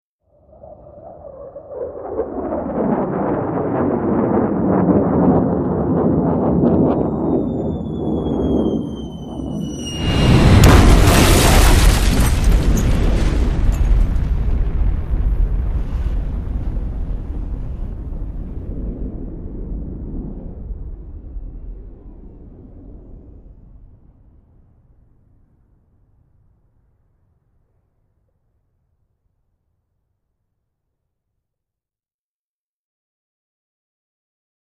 Bomber Jet | Sneak On The Lot
Bomber Jet Plane Over, Bomb Drop Whistle, Impact And Explosion With Debris.